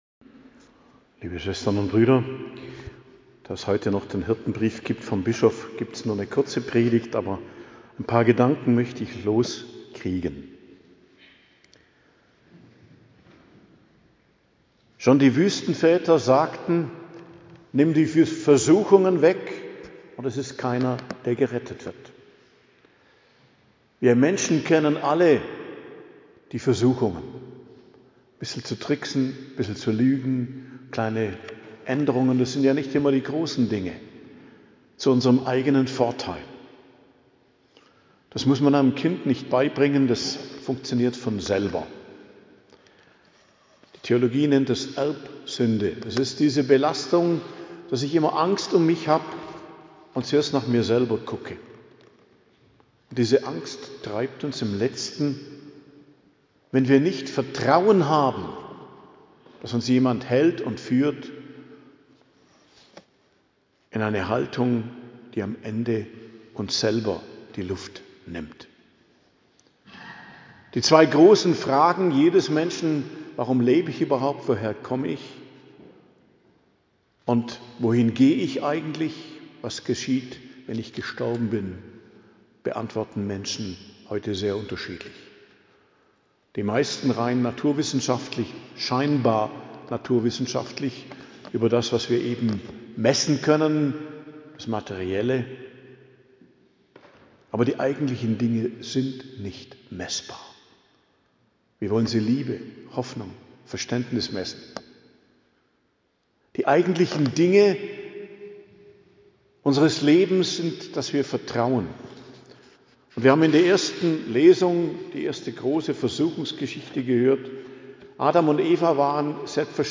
Predigt zum Ersten Fastensonntag, 22.02.2026